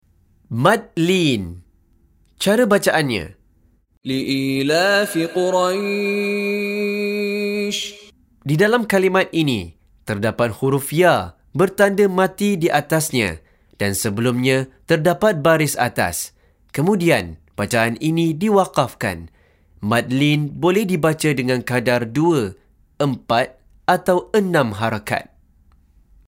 Penerangan Hukum + Contoh Bacaan dari Sheikh Mishary Rashid Al-Afasy
Mad Lin : Bacaan lunak atau lembut dengan intonasi bacaan yang lunak dan lembut.